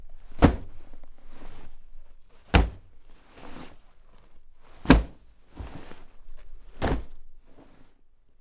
household